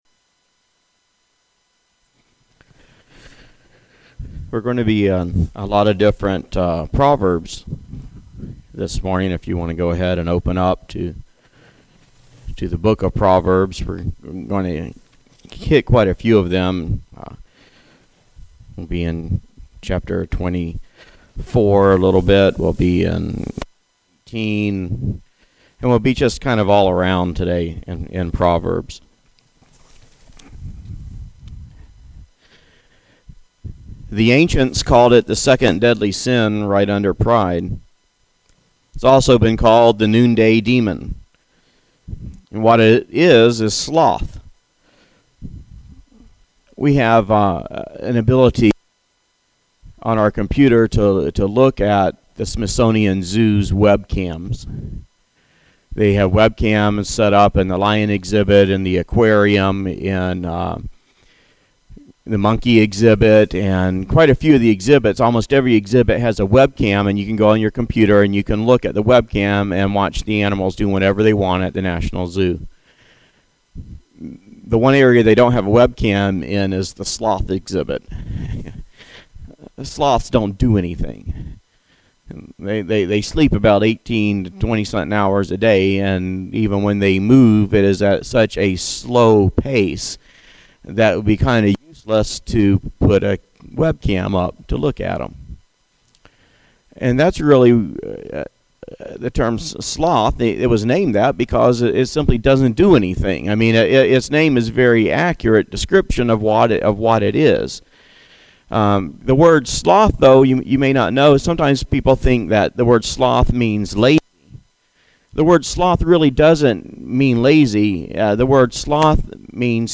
A sermon preached on the sinful habit of sloth